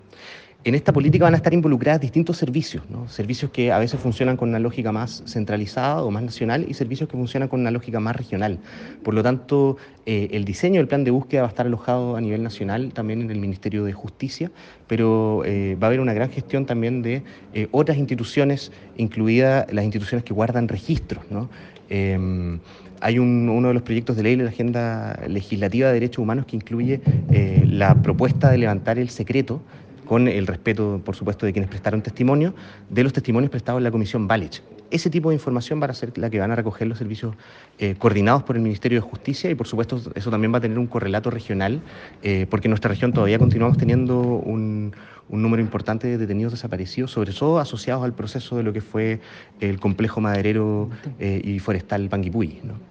Así lo precisó a RioenLinea, el seremi de Gobierno en Los Ríos, Juan Sebastián Guerra quien detalló que son varias las instituciones que van a formar parte del programa.